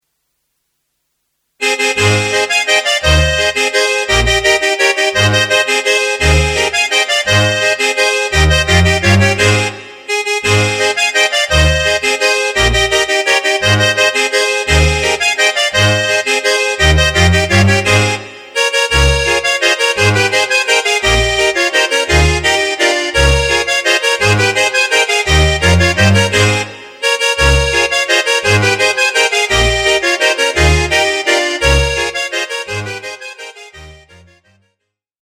Ab-Dur